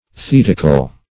Search Result for " thetical" : The Collaborative International Dictionary of English v.0.48: Thetical \Thet"ic*al\, a. [Gr.